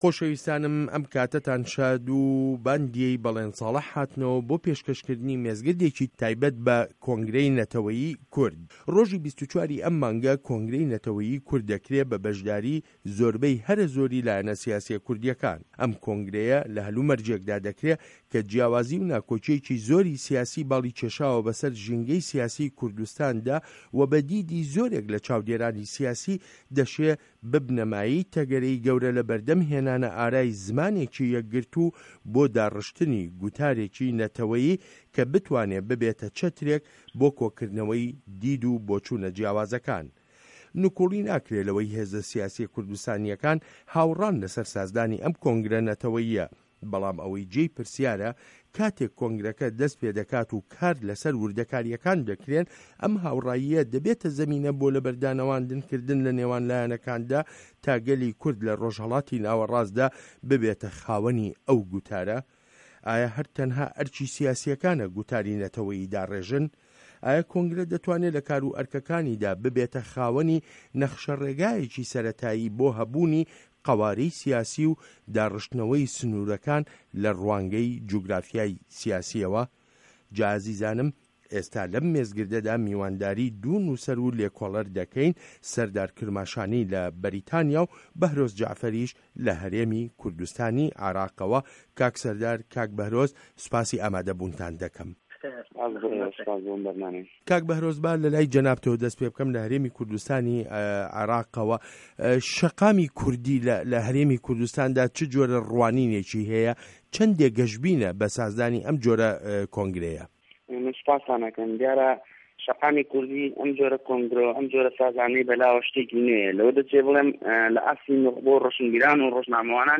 مێزگرد: کۆنگره‌ی نه‌ته‌وه‌یی کورد